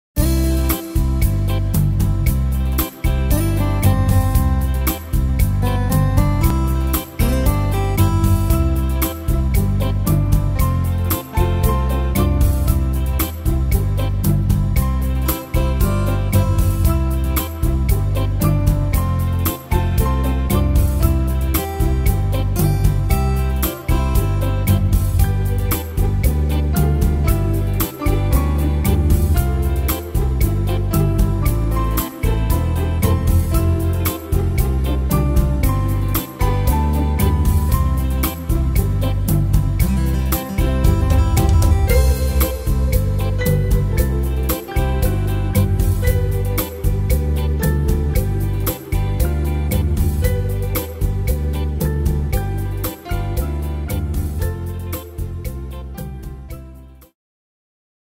Tempo: 115 / Tonart: C-Dur